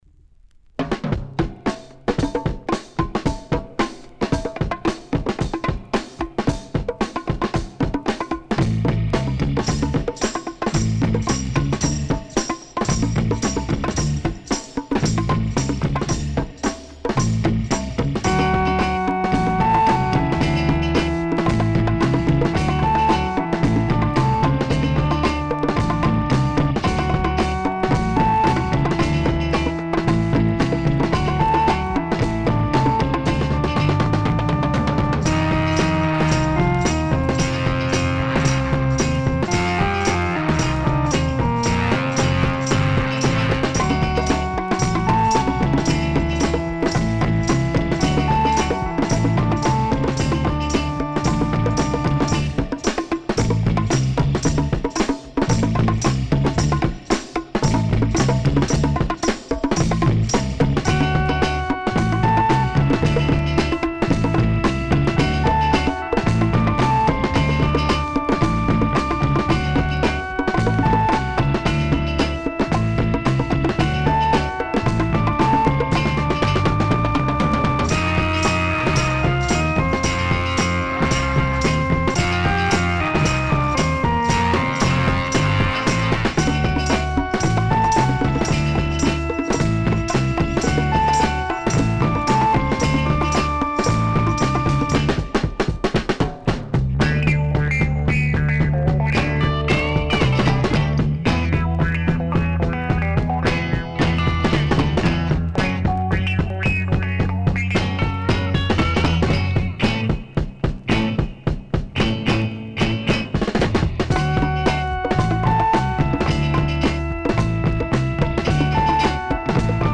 Published January 10, 2011 Garage/Rock Comments
modern Spanish (Iberian) rock genre